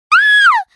Download Lulu League of Legends Grito sound effect for free.
Lulu League Of Legends Grito